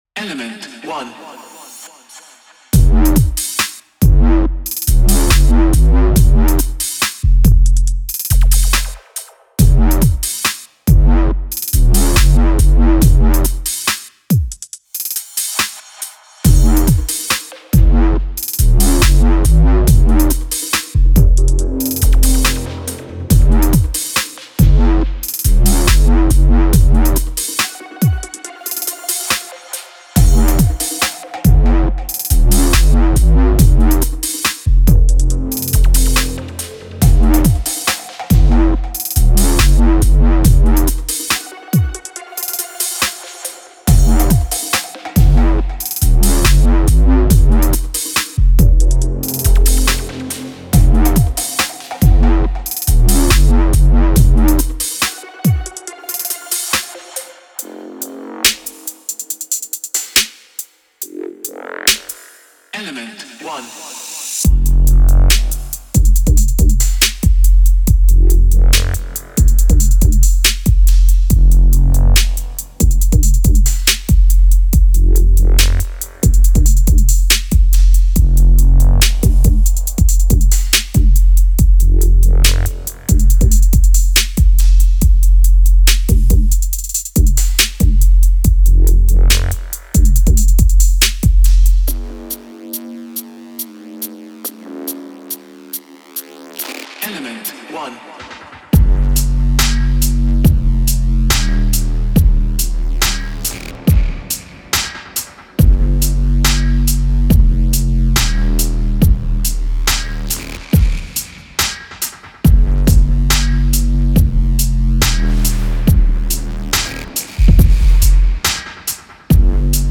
確固たるハーフタイム140グルーヴほど観客を動かすものはありません。
重量感のあるサブ、歪んだグロウル、Reese系のテクスチャー、パンチの効いたドンク、中域のワブまで揃っています。
デモサウンドはコチラ↓
Genre:Dubstep